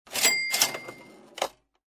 cash-register-antiq-bell-op.ogg_3d61baab